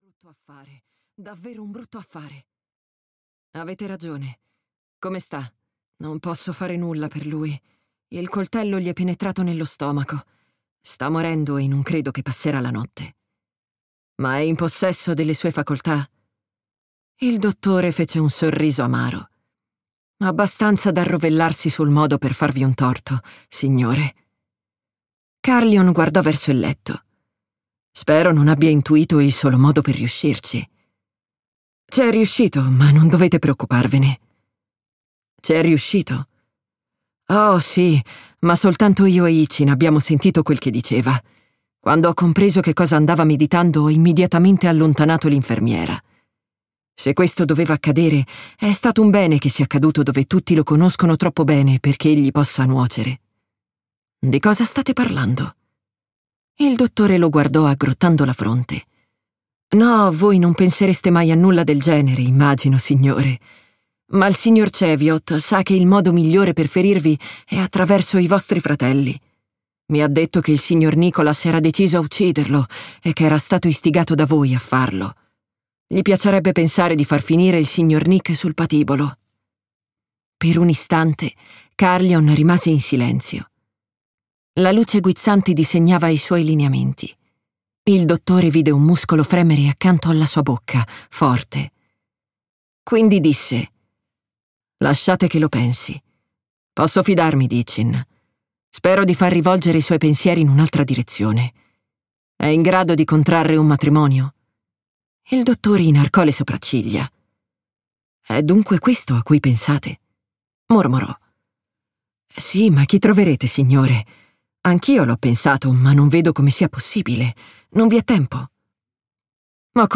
"La vedova riluttante" di Georgette Heyer - Audiolibro digitale - AUDIOLIBRI LIQUIDI - Il Libraio